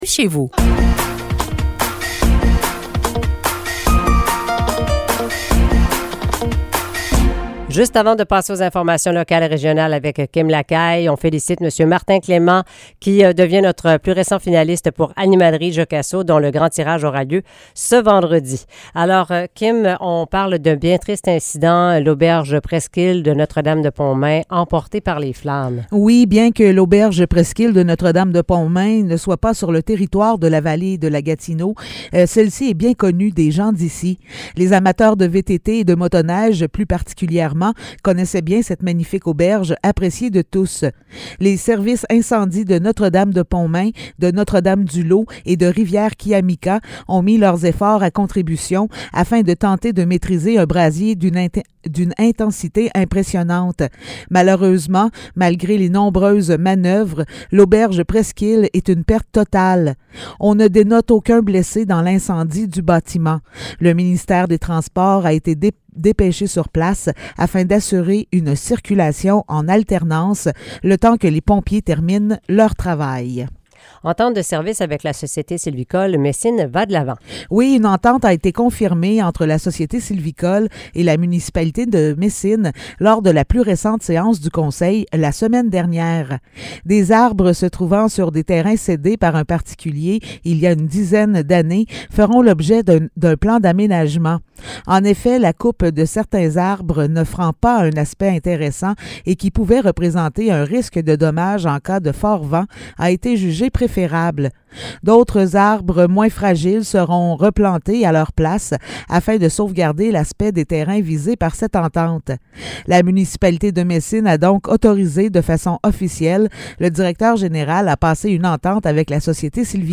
Nouvelles locales - 7 mars 2023 - 8 h